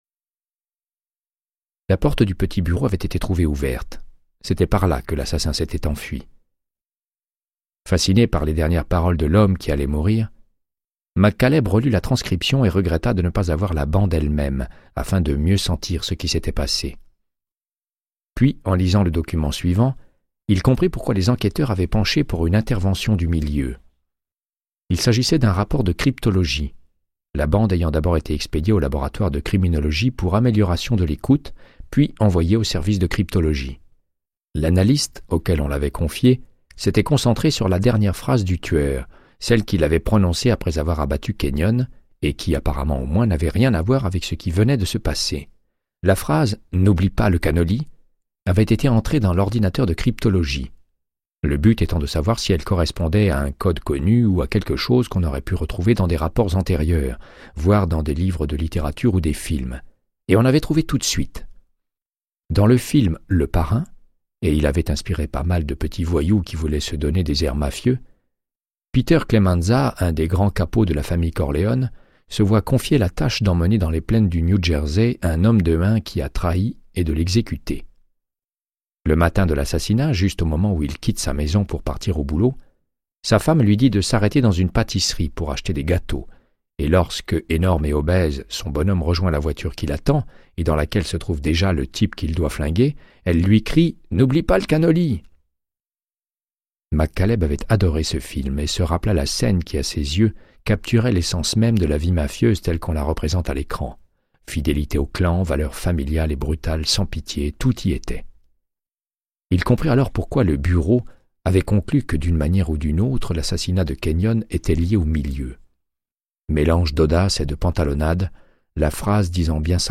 Audiobook = Créance de sang, de Michael Connelly - 102